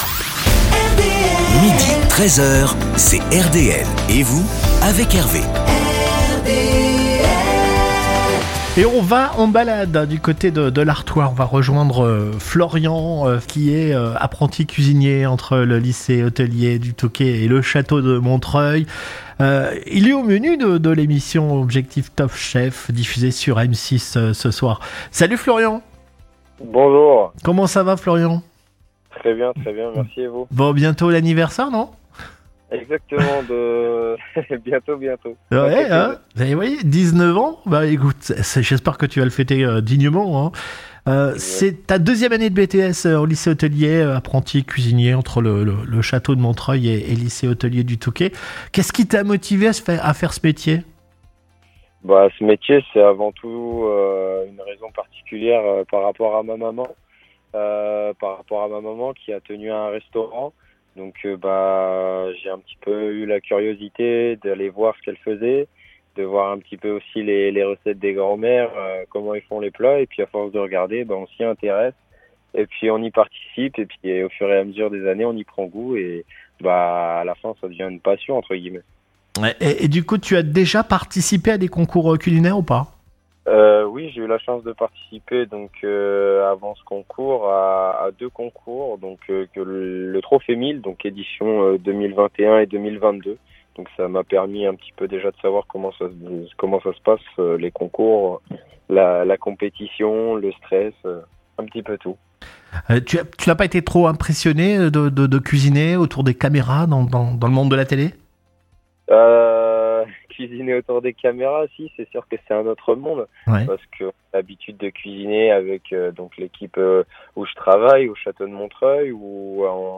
En interview